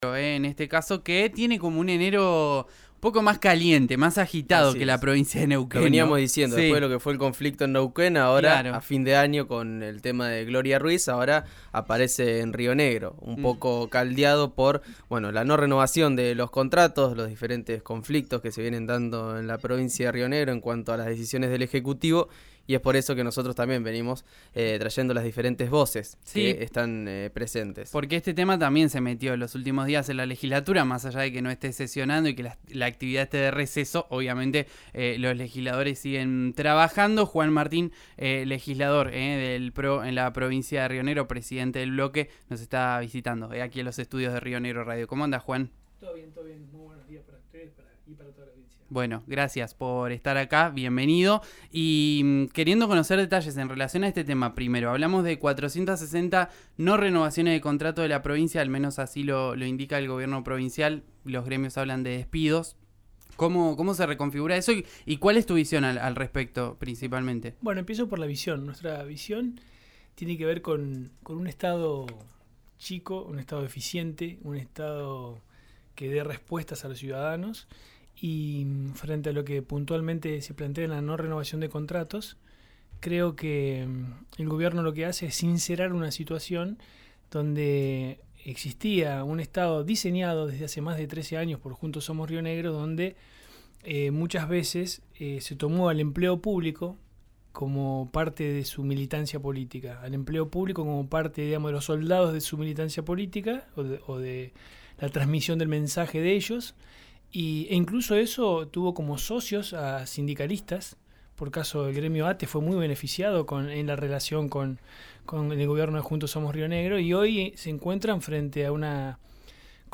El legislador y presidente del PRO Río Negro visitó los estudios de RÍO NEGRO RADIO.